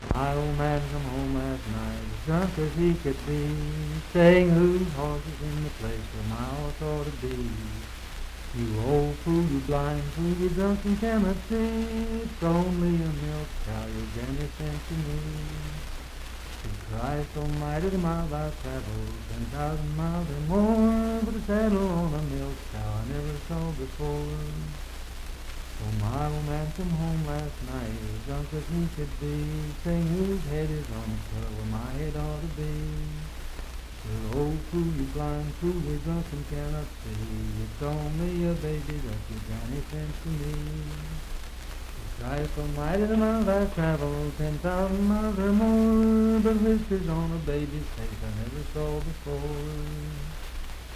Unaccompanied vocal music
Verse-refrain 6(4).
Voice (sung)